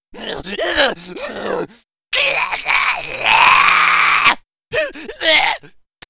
LSL-Scripts/Zombies/sounds/Monster1.wav at 7d095b2c0c282ee20d368c8ea2b7a28a372cef1a
Monster1.wav